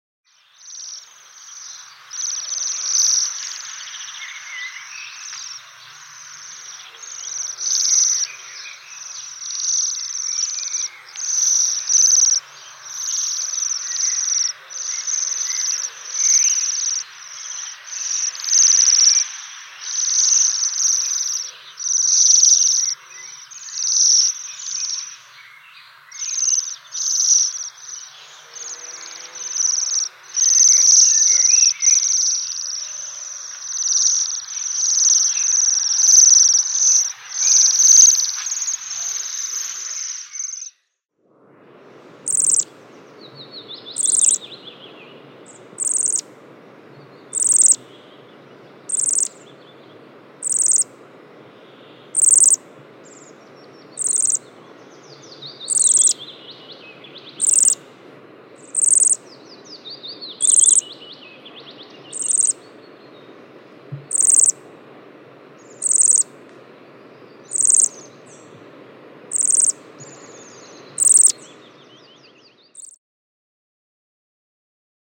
Sidensvans
sidensvans.mp3